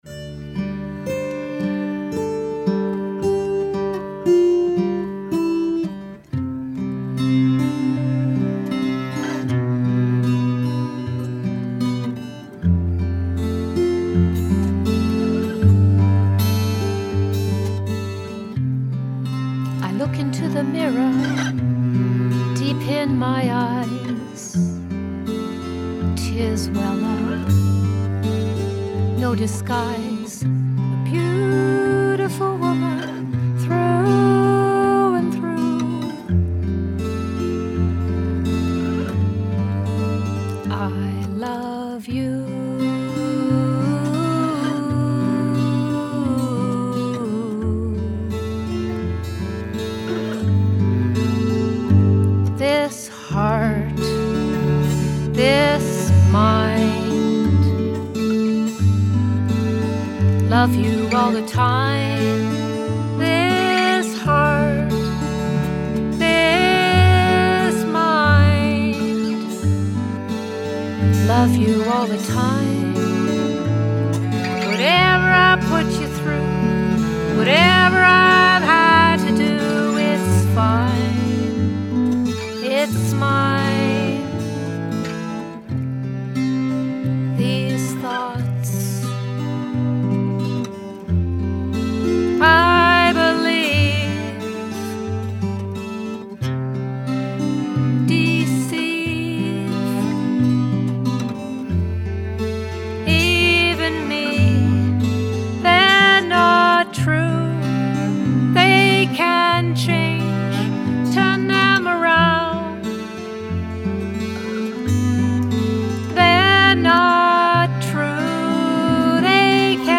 love-what-is-cello-way-up.mp3